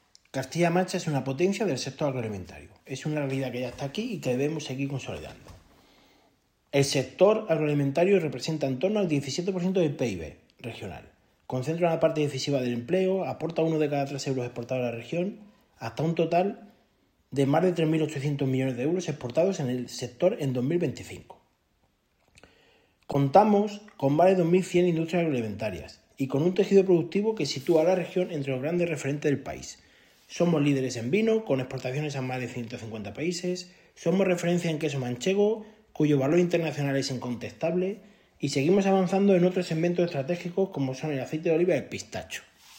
El viceconsejero de la Política Agraria Común y Política Agroambiental, José Manuel Martín Aparicio, lo ha expresado en estos términos durante su ponencia ‘Castilla-La Mancha ante el nuevo escenario global’, con la que ha clausurado la Jornada Agroindustria 2026 ‘Castilla-La Mancha ante el nuevo escenario global’, organizada por la Asociación para el Progreso de la Dirección (APD) en Alcázar de San Juan, y en la que ha estado acompañado por la delegada de Agricultura, Ganadería y Desarrollo Rural, Amparo Bremard.